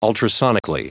Prononciation du mot ultrasonically en anglais (fichier audio)
Prononciation du mot : ultrasonically
ultrasonically.wav